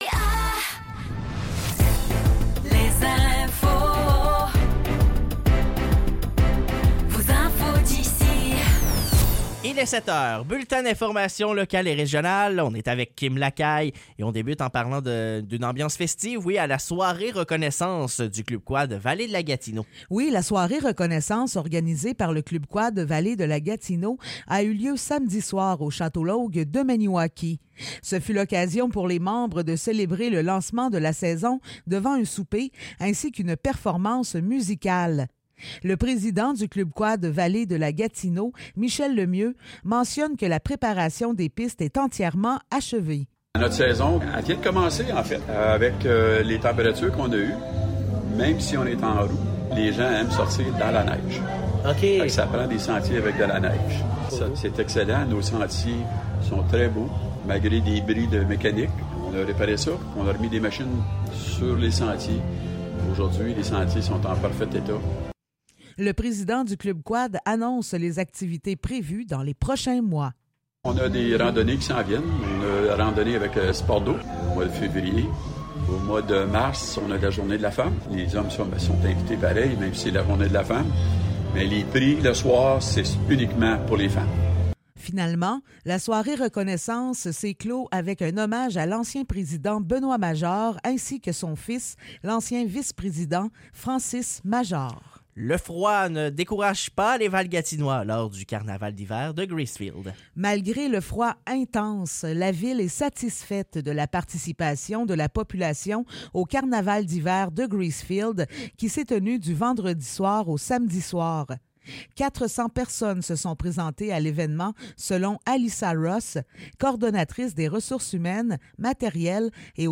Nouvelles locales - 22 janvier 2024 - 7 h